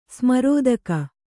♪ smarōdaka